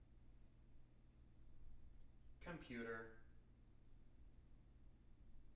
wake-word
tng-computer-71.wav